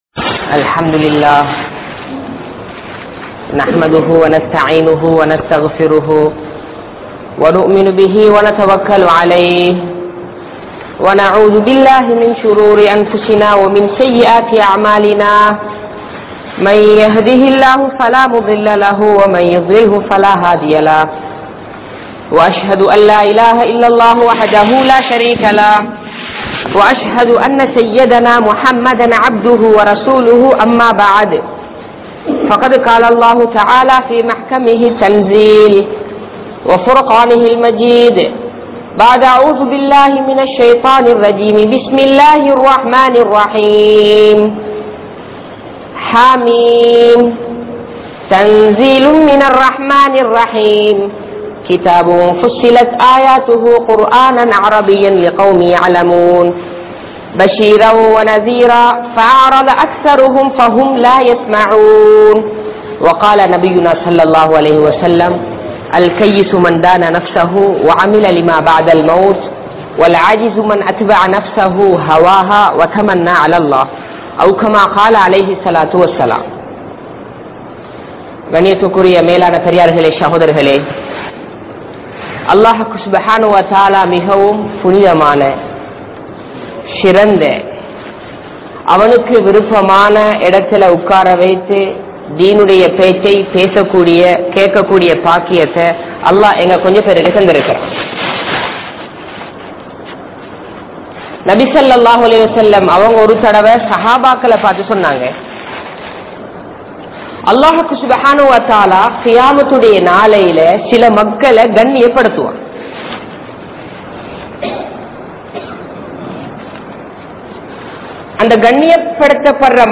Allah`vukku Nantri Sealuthungal (அல்லாஹ்வுக்கு நன்றி செலுத்துங்கள்) | Audio Bayans | All Ceylon Muslim Youth Community | Addalaichenai
Grand Jumua Masjidh(Markaz)